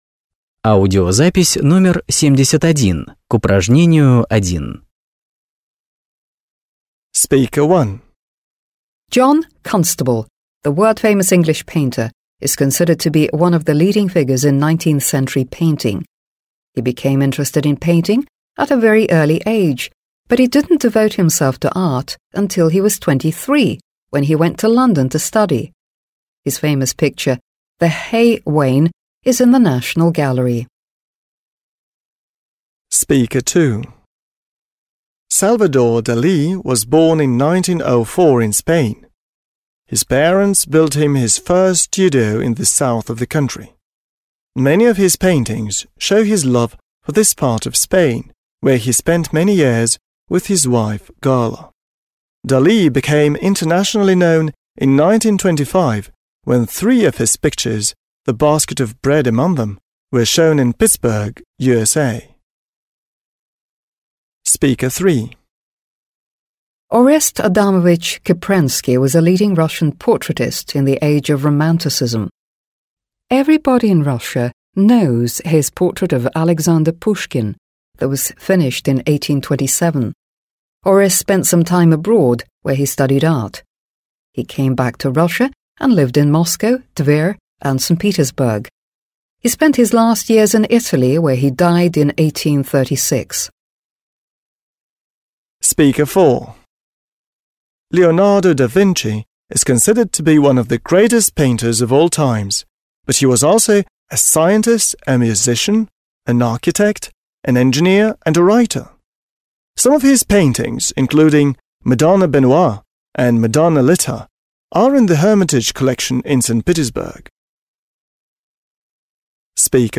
1. Послушайте, как пять человек говорят о всемирно известных художниках, (71), и сопоставьте говорящих (1-5) с утверждениями (a-f).